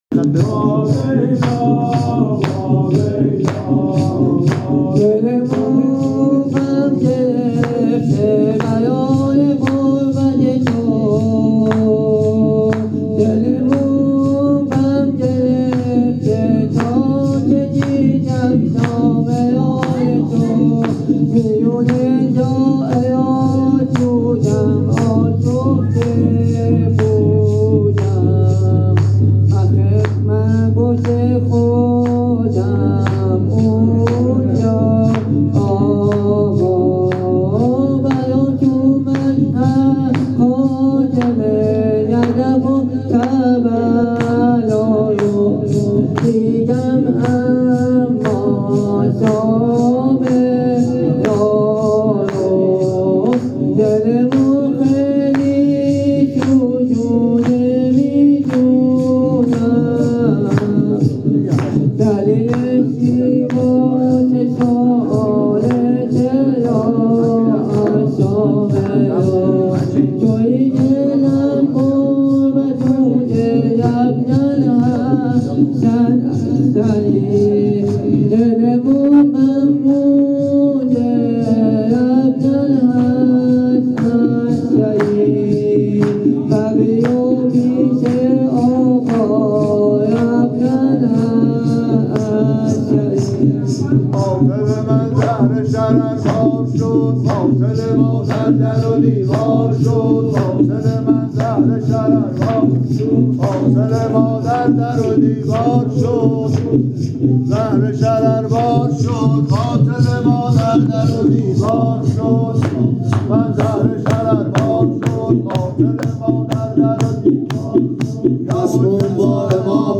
زمینه شهادت امام حسن عسکری
هیت هفتگی عشاق العباس تهران